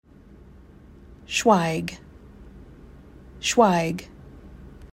Access brief introduction Download hi-res portrait How to pronounce Schwaig
schwaig-pronunciation.mp3